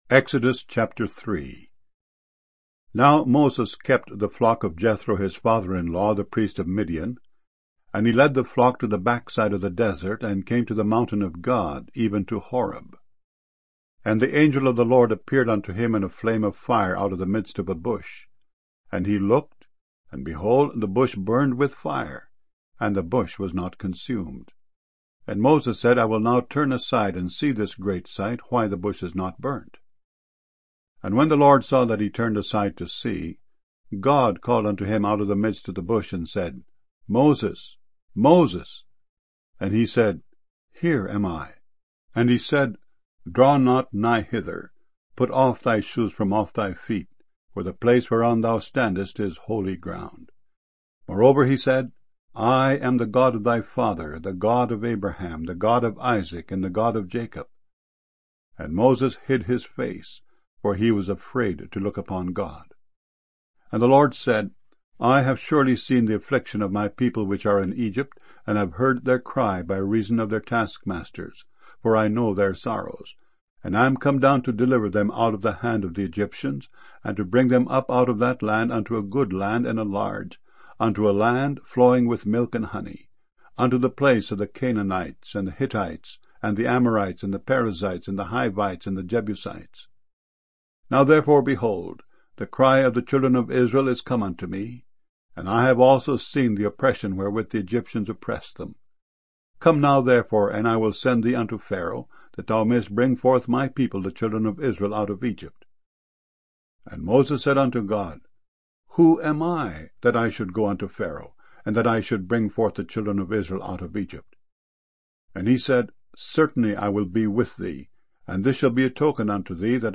00 Streaming MP3 Audio Bible files mono 32 kbs small direct from wav files